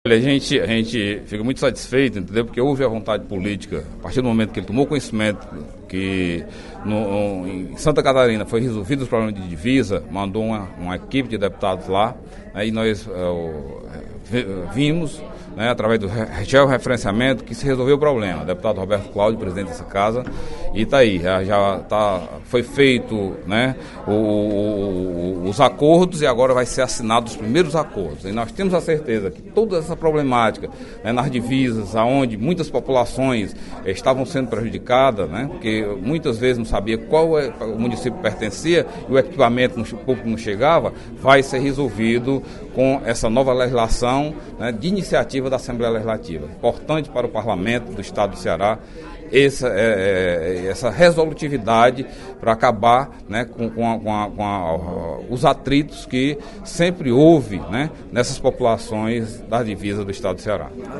O deputado Delegado Cavalcante (PDT) afirmou, na sessão plenária desta quinta-feira (29/03) da Assembleia Legislativa, que a assinatura do pacto para definir os limites entre nove municípios do Ceará trará benefícios para as populações destas cidades.